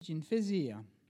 Localisation Saint-Hilaire-de-Riez
Catégorie Locution